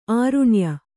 ♪ āruṇya